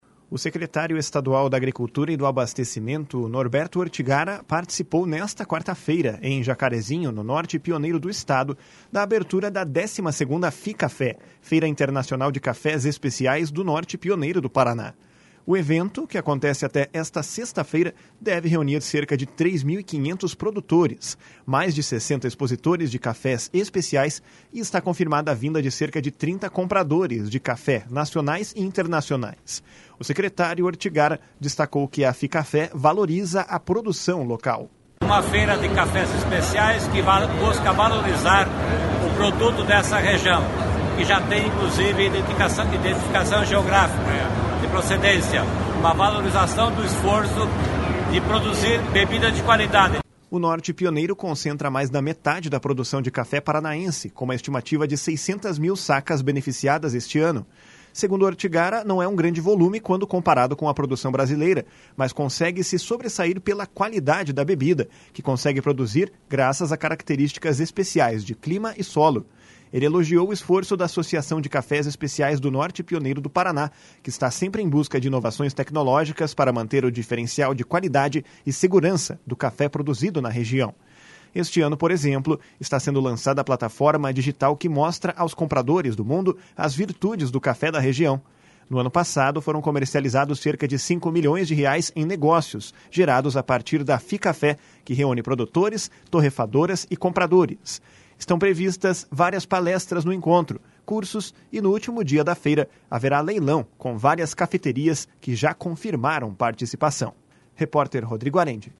O secretário Ortigara destacou que a Ficafé valoriza a produção local. // SONORA NORBERTO ORTIGARA //